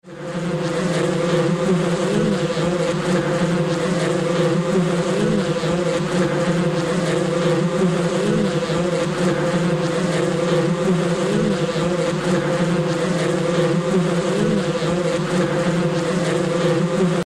Звуки осы
Потревожили осиное гнездо